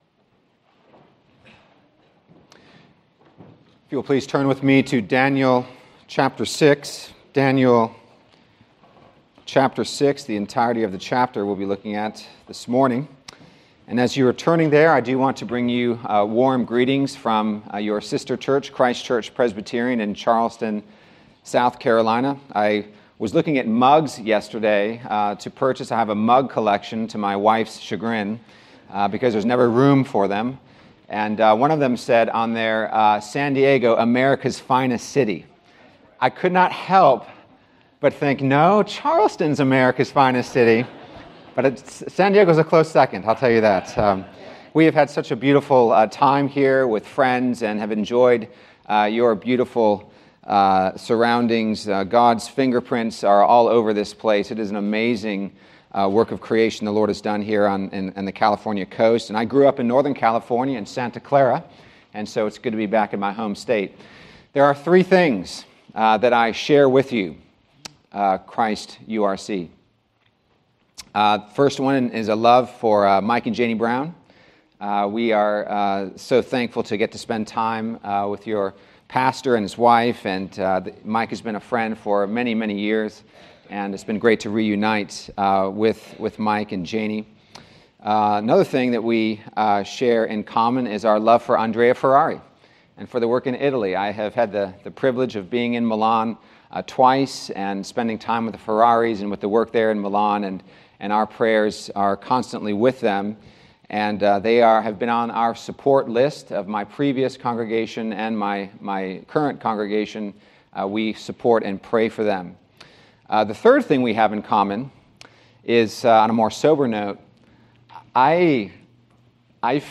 Daniel — Sermons — Christ United Reformed Church